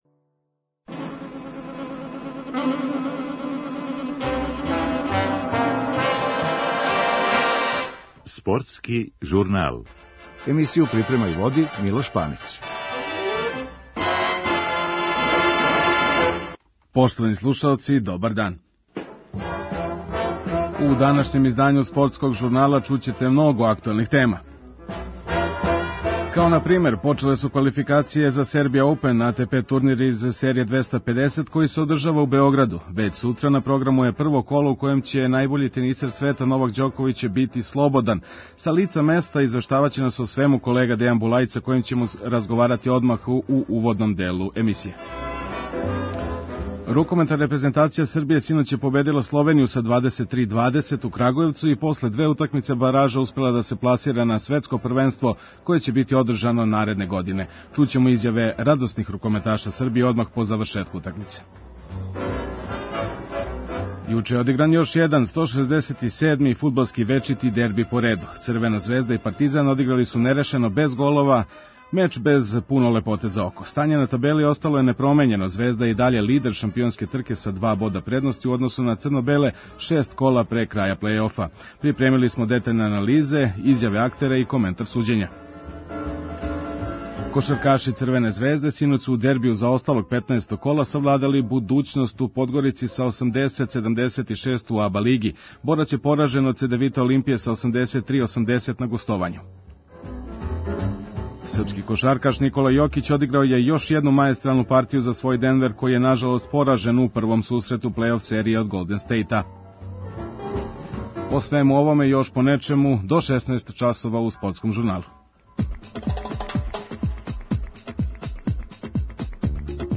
Данас су почеле квалификације за АТП турнир из серије 250 који се одржава у Београду, под називом ‘’Serbia Open’’. Већ сутра је на програму такмичење у првом колу, а своје конфренције за новинаре током дана одржали су Доминик Тим, Андреј Рубљов и Новак Ђоковић, чију ћете изјаву чути у нашој емисији.